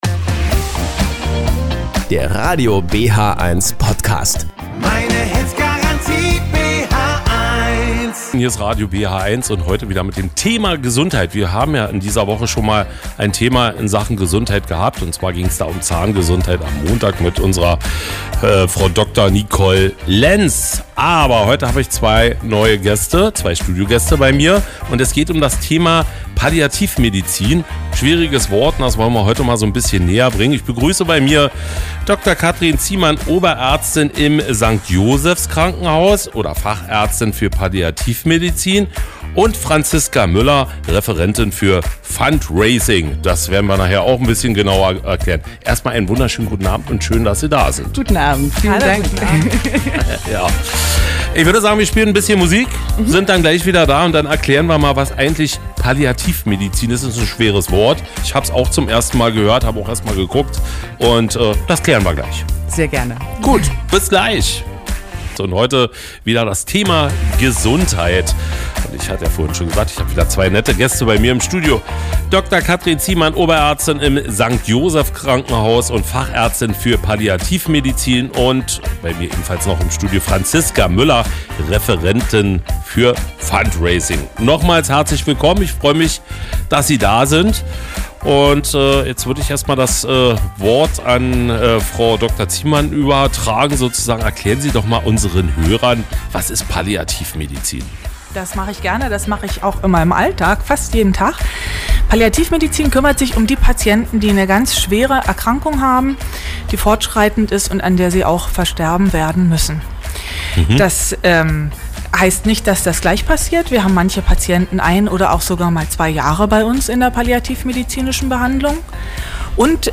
Heute ging es noch mal um das Thema Gesundheit, um Palliativmedizin und Fundraising. Dazu hatten wir nette Studiogäste